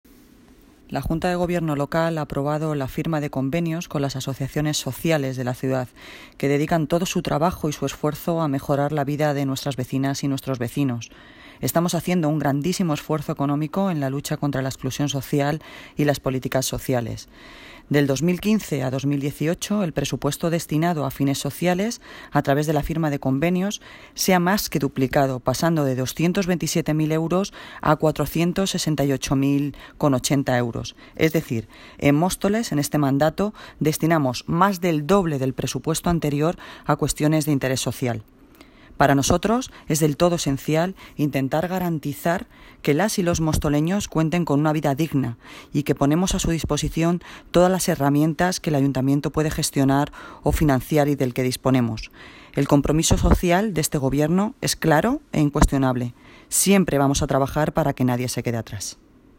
Audio - Noelia Posse (Alcaldesa de Móstoles) Sobre subvenciones servicios sociales